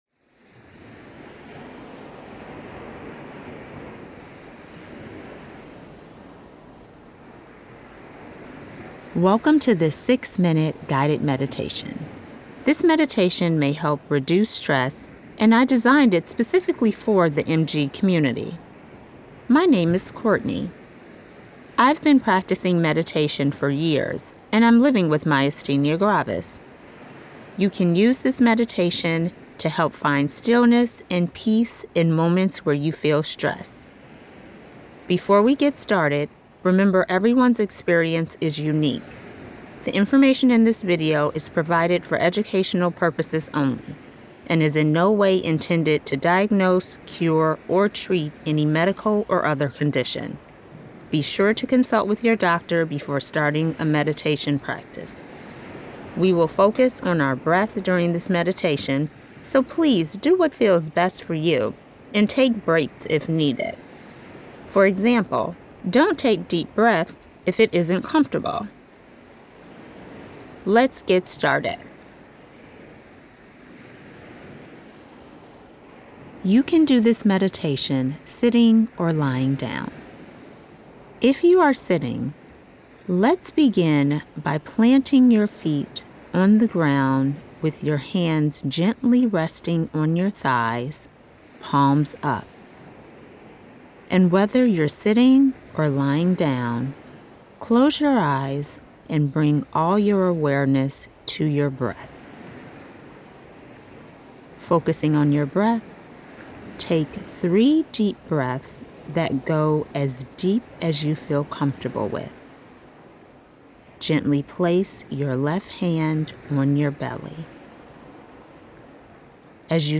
MG-United-Guided-Meditation.mp3